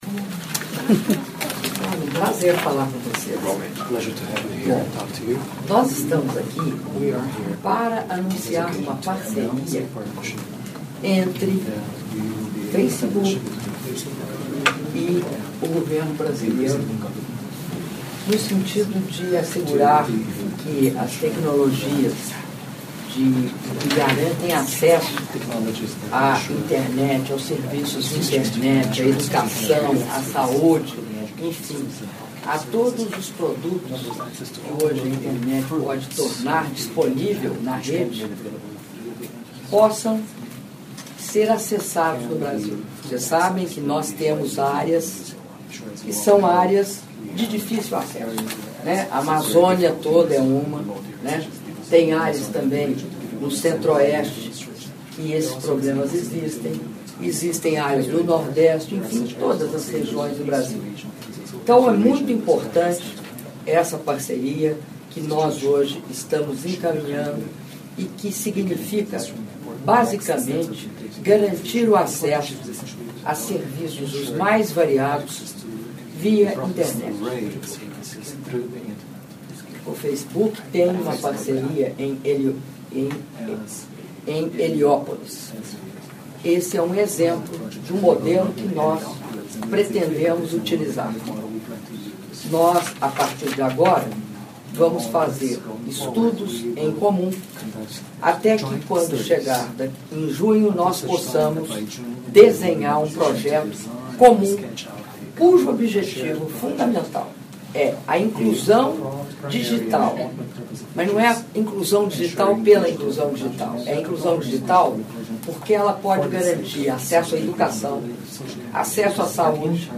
Áudio da declaração da Presidenta da República, Dilma Rousseff, após encontro com o Presidente do Facebook, Mark Zuckerberg - Cidade do Panamá/Panamá (3min06s)